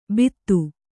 ♪ bittu